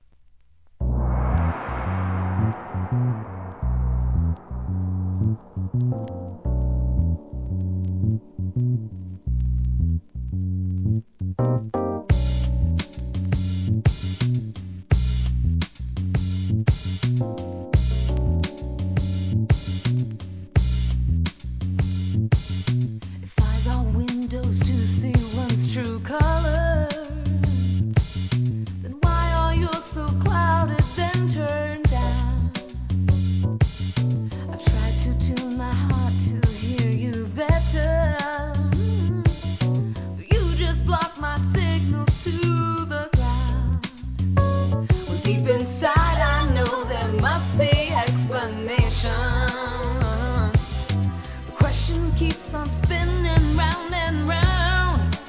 ACID JAZZ